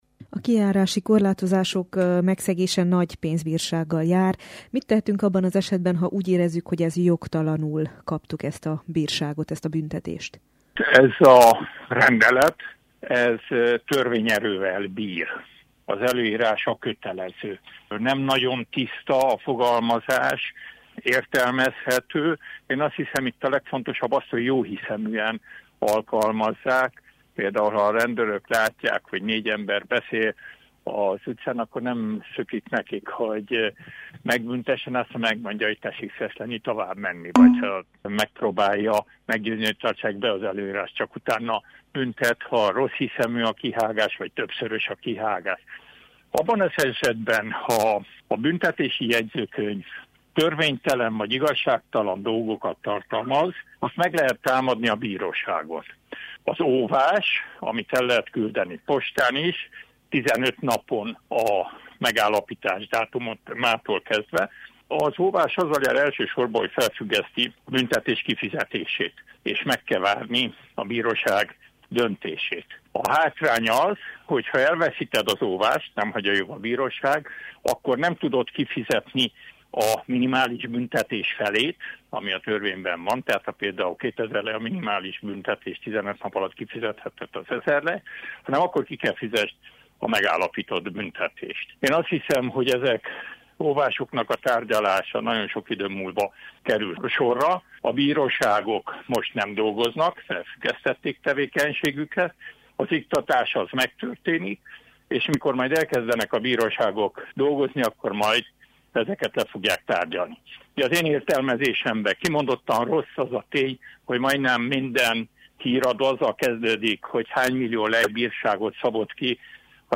Frunda György jogászt kérdezte